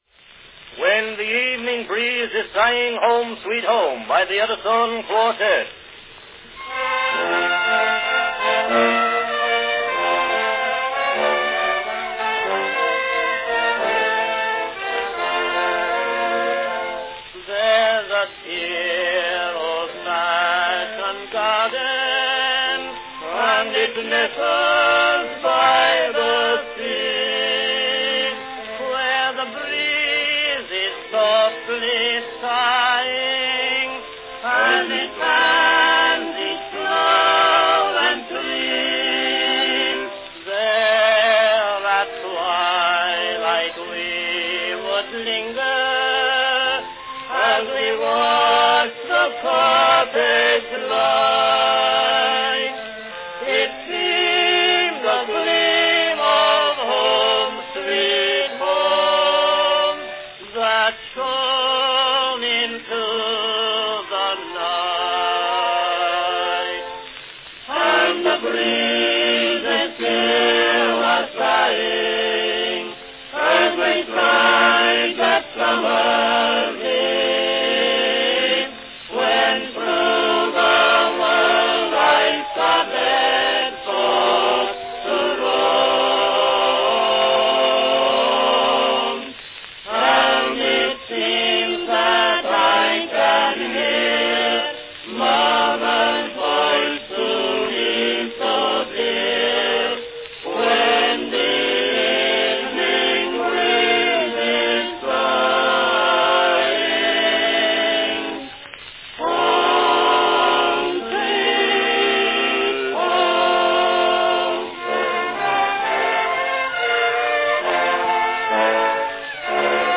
the sentimental ballad
Category Quartette
wax cylinders